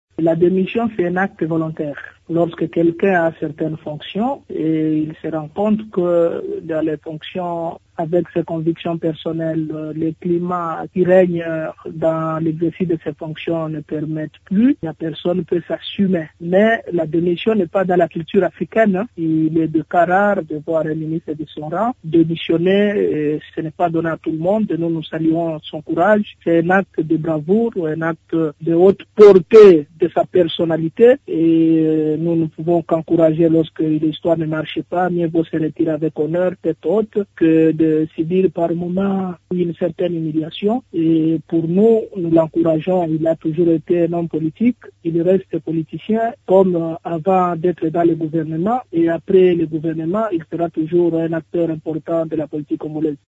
La démission du vice-ministre, ministre de la Justice et garde des sceaux Célestin Tunda Ya Kasende est un acte de bravoure, réagit Lucain Kasongo, député national et président de la commission PAJ à l’Assemblée nationale.